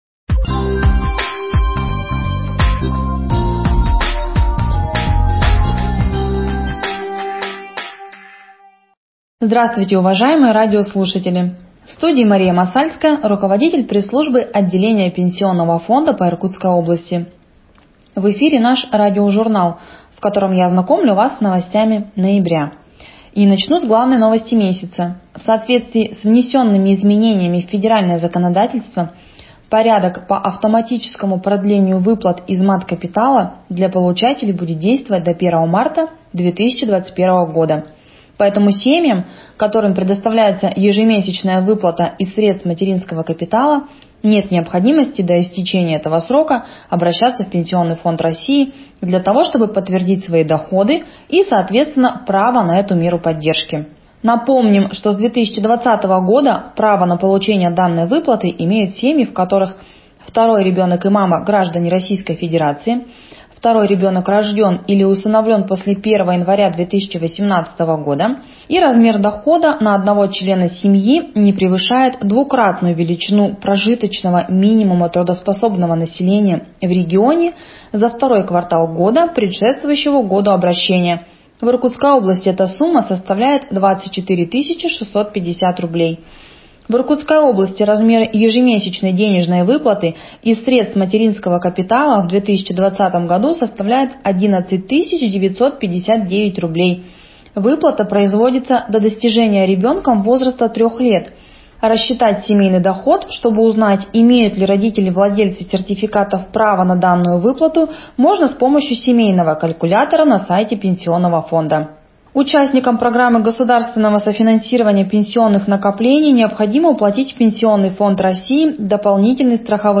Радиожурнал "Информирует ПФ РФ" 01.12.2020